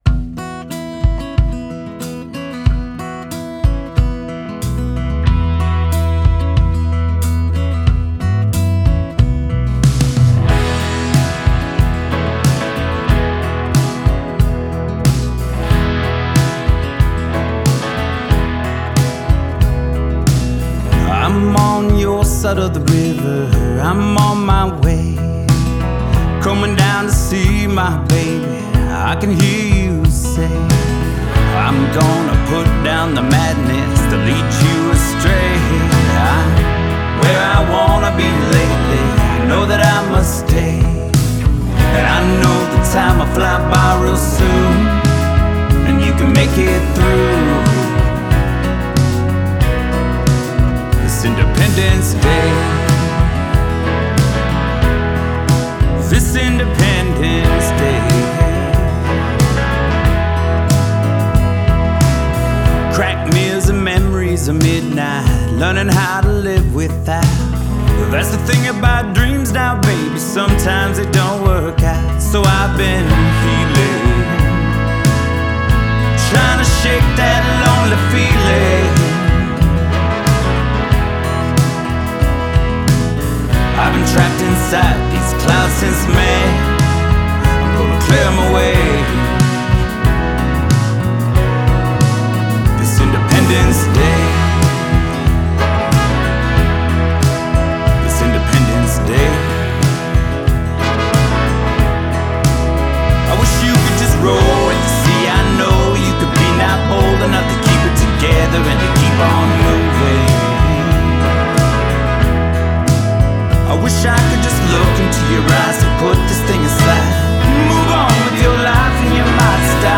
An acoustic and horn driven breakup song